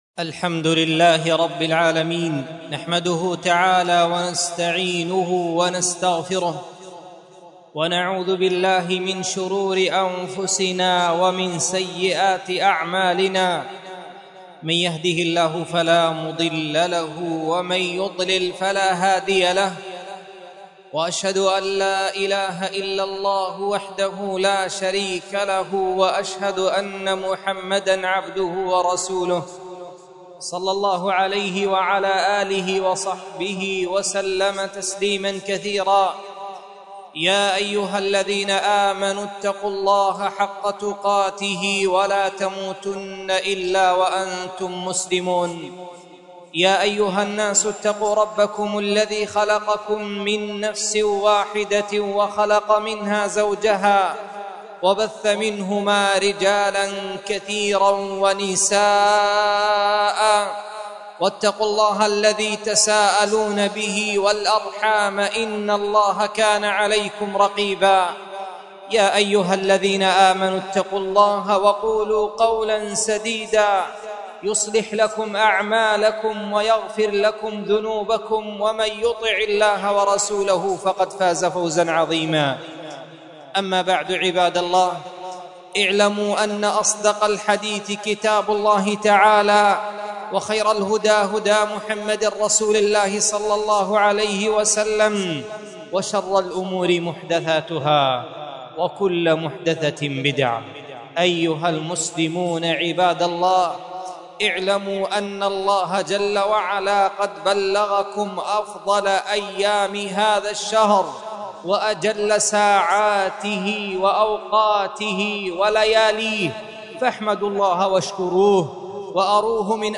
مسجد درة عدن محافظة عدن حرسها الله